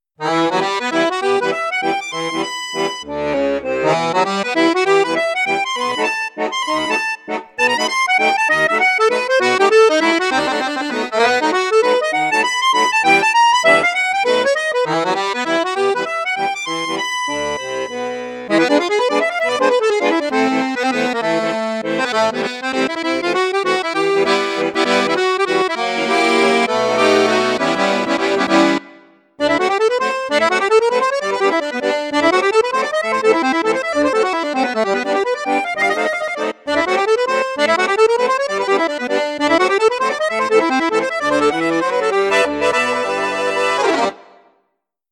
registre 2 flutes + piccolo